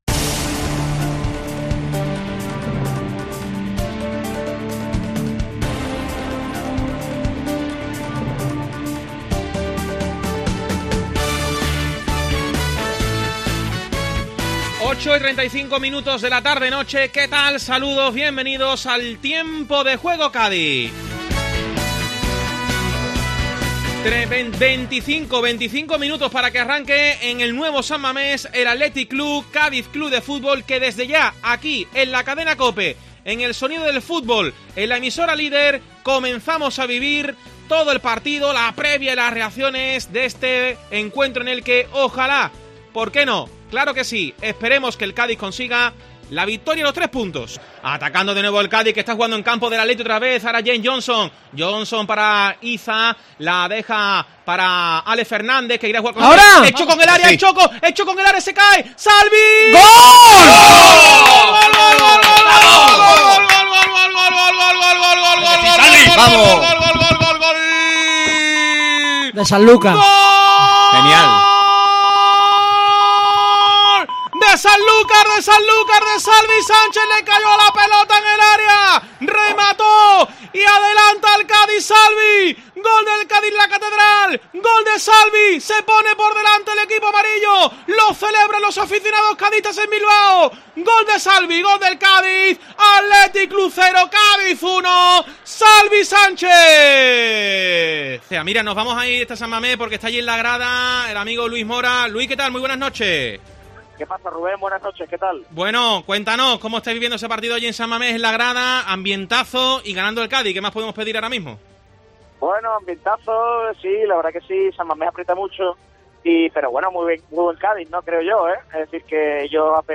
Escucha el resumen sonoro del partido con el gol de Salvi que dio el triunfo, una llamada a Bilbao y la emoción del tiempo de descuento
El resumen sonoro del Athletic 0-1 Cádiz
Escucha el resumen sonoro del partido con el gol de Salvi, el tiempo de descuento final y una llamada al descanso a un seguidor cadista que se encontraba en las gradas de San Mamés.